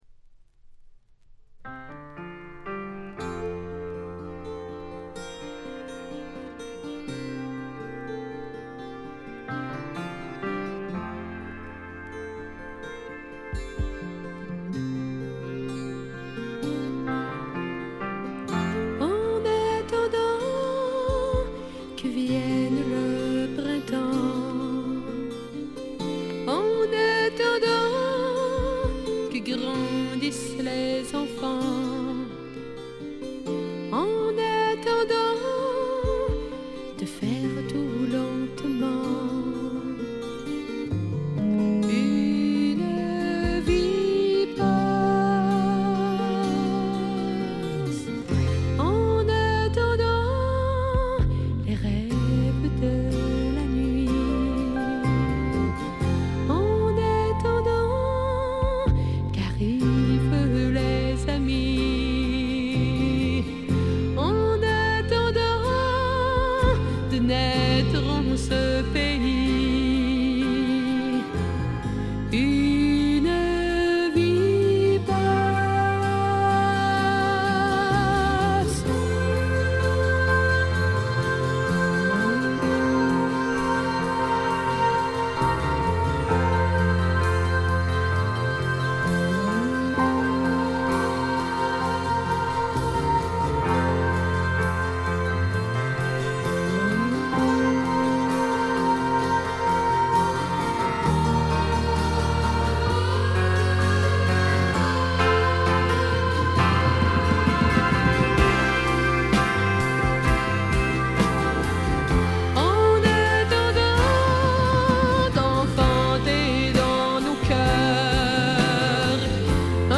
これ以外はほとんどノイズ感無し。
カナダ・ケベックを代表する兄妹フレンチ・ヒッピー・フォーク・デュオによる名盤です。
本作は特に幻想的な表現に磨きがかかっており、浮遊感漂う夢見心地な感覚は絶品ですね。
試聴曲は現品からの取り込み音源です。
Guitar, Vocals